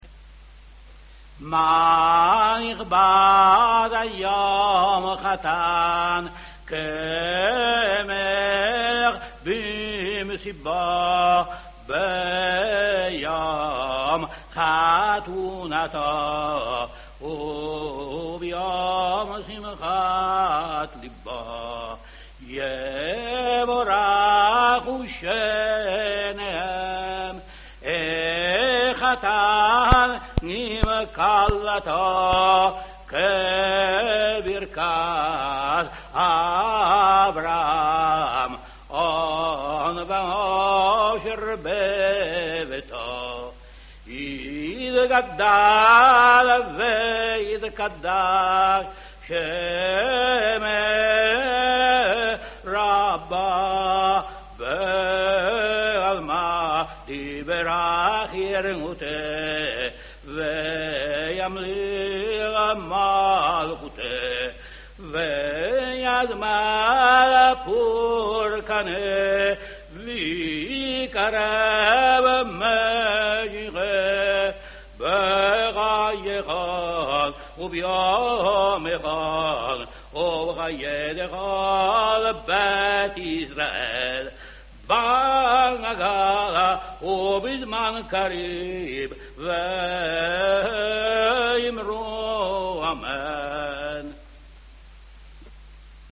Registrazioni Storiche
107 Kaddish per Purim, Livorno, rav Elio Toaff